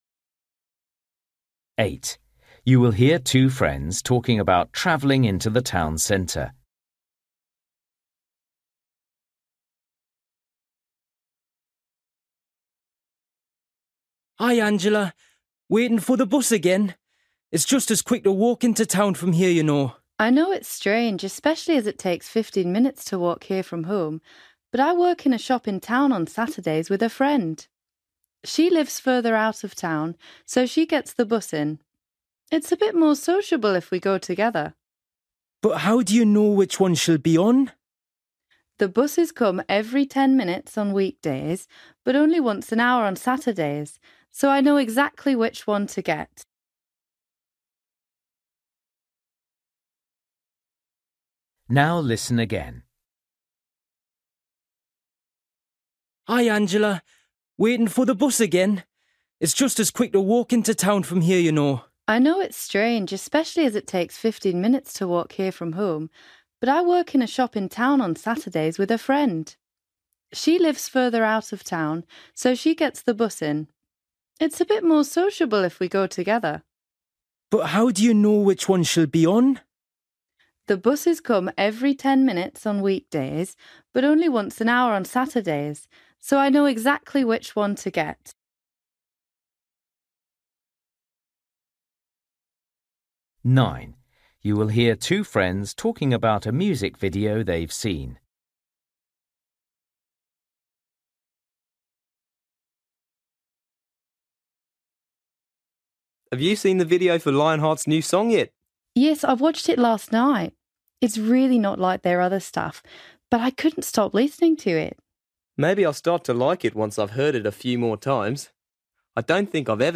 Listening: everyday short conversations
8   You will hear two friends talking about travelling into the town centre. Why does the girl prefer taking the bus to the town centre?
12   You will hear a boy telling his friend about a family visit to some relatives. How did he feel about it?